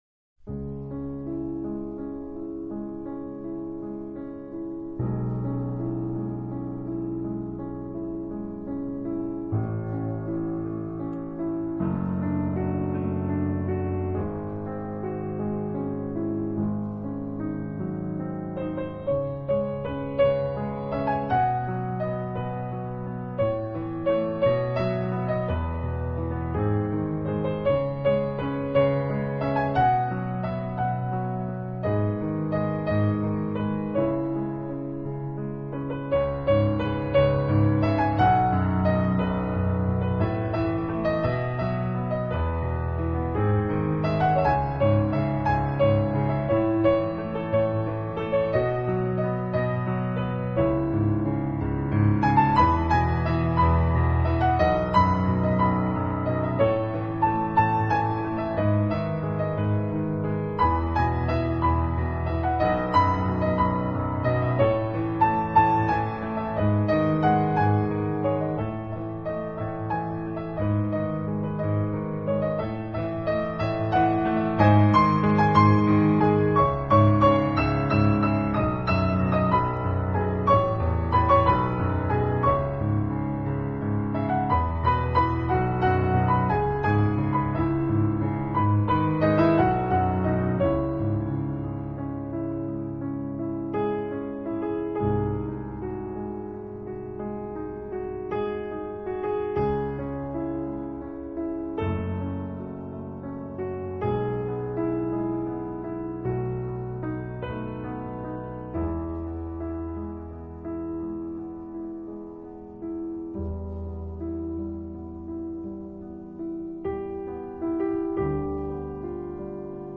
威钢琴演奏及以DSD格式录制，带给给乐迷全新的感受!